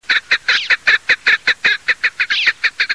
Alectoris Chukar
Φωνή: Δυνατή, με χαρακτηριστικό κάκαρα-κακ, κάκαρα κακ.
Alectoris.chukar.mp3